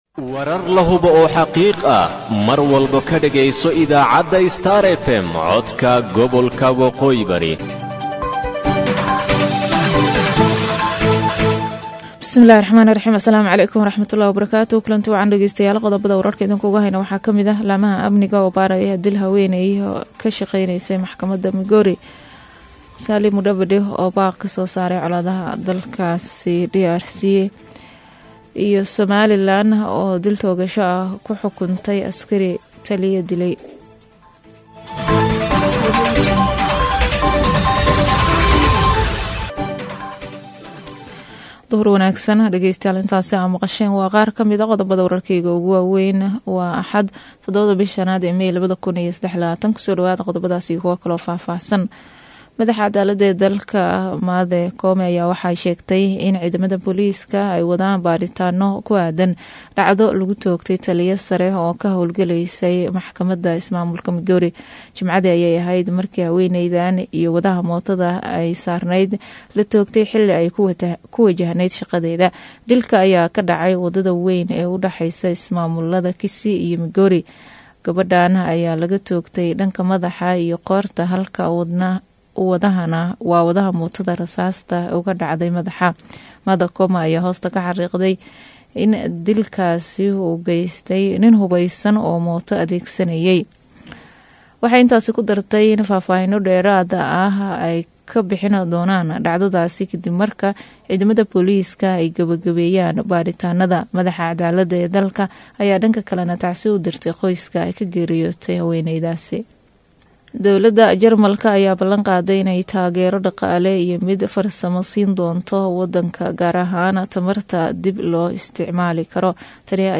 WARKA DUHURNIMO EE IDAACADDA STAR FM